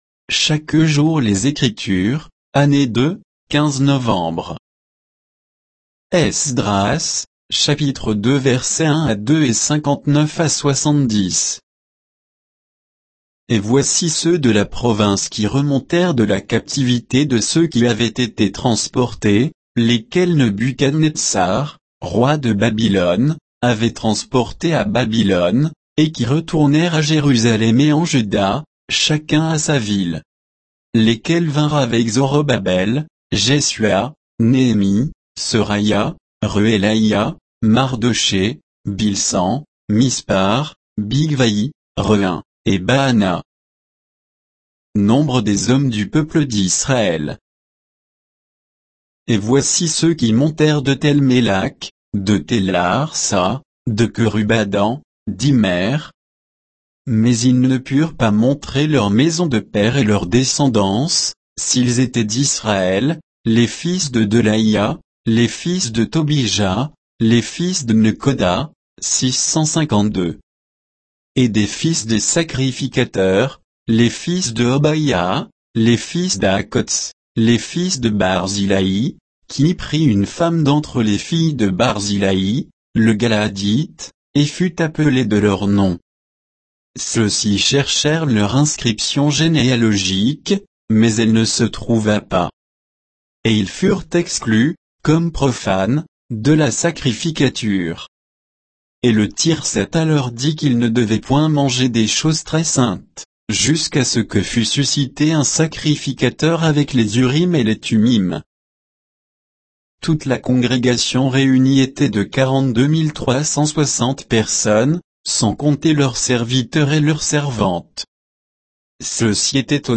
Méditation quoditienne de Chaque jour les Écritures sur Esdras 2